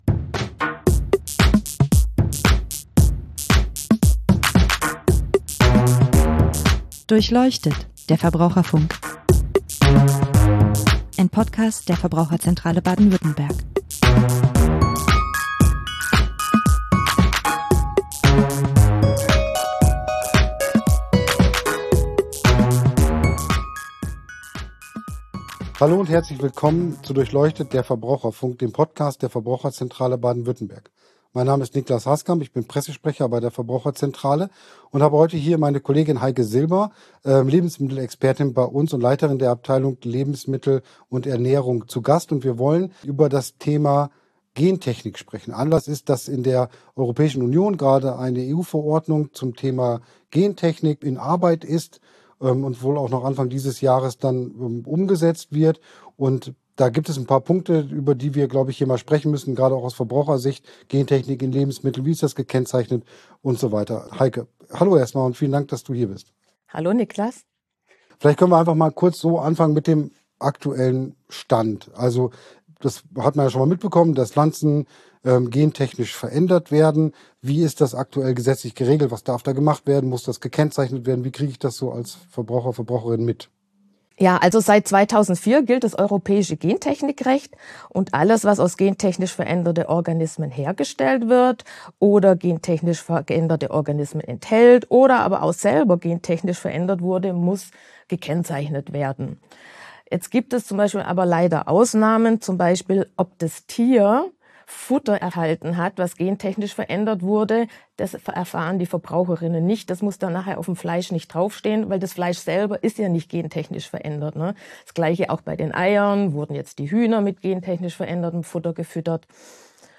Expertin für Lebensmittel und Ernährung